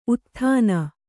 ♪ utthāna